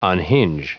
Prononciation du mot unhinge en anglais (fichier audio)
Prononciation du mot : unhinge